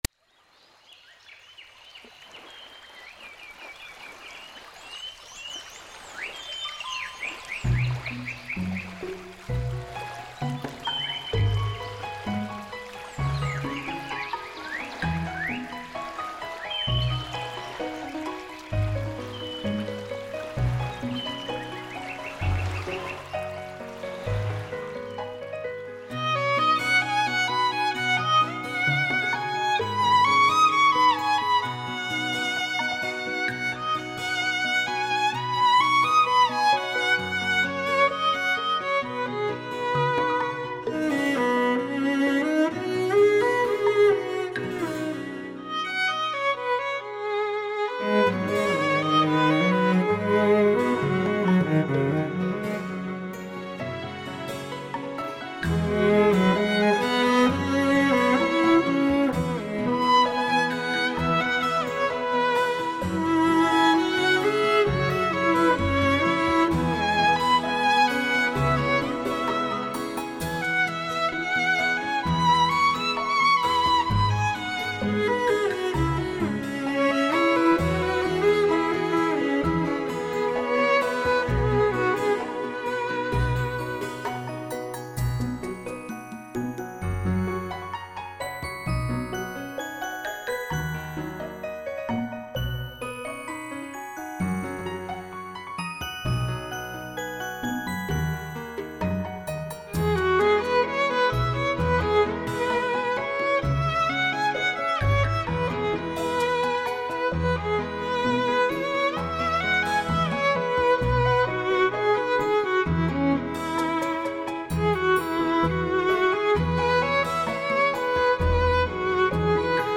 带来超乎想像震撼性的6.1环绕新体验。
空灵清澈天籁之音，九寨沟如画风光，